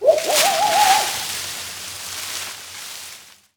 foxhole.wav